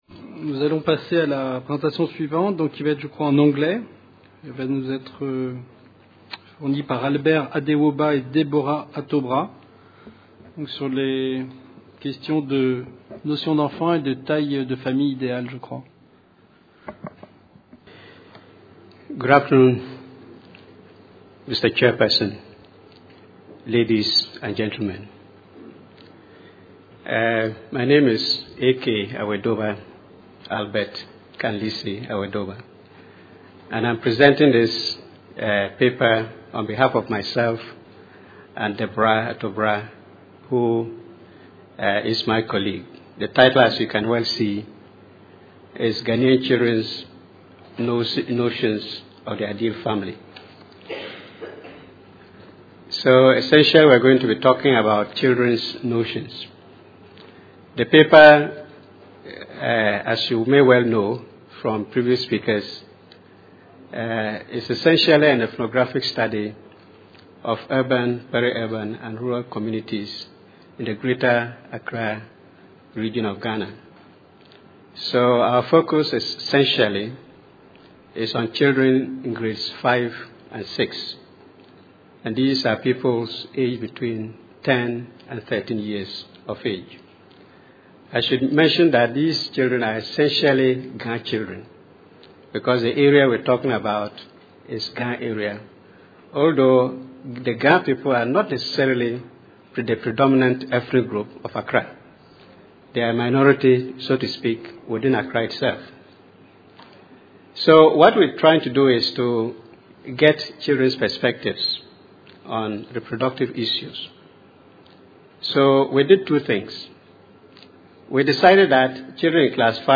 Réduction de la mortalité maternelle - Dakar 2010 : Ghanaian Children’s Notions of the Composition of and Ideal Family. Conférence enregistrée dans le cadre du Colloque International Interdisciplinaire : Droit et Santé en Afrique. Réduction de la mortalité maternelle en Afrique Sub-saharienne, mieux comprendre pour mieux agir. 1ère Session : Fécondité, sexualité et planning familial.